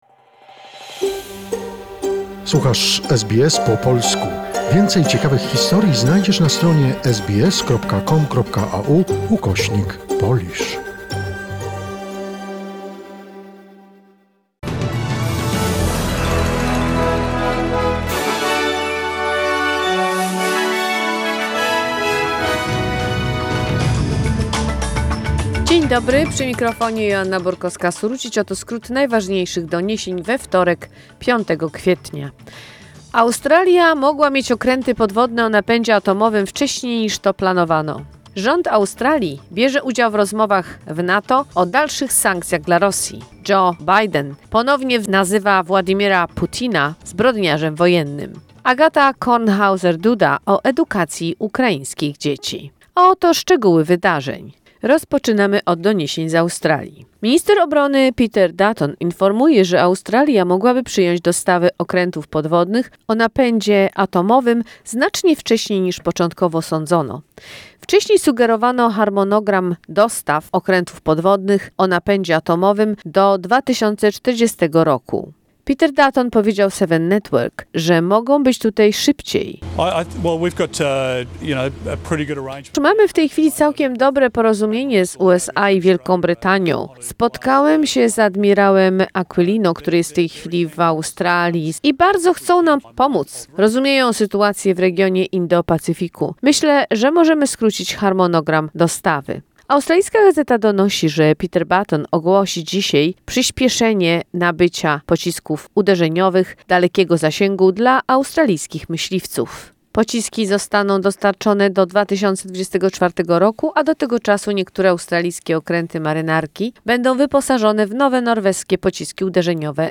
SBS News Flash in Polish, 5 April 2022